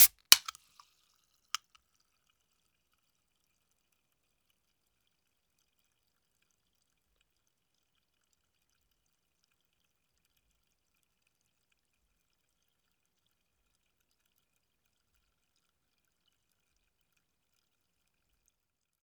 Can Beer Open Sound
household
Can Beer Open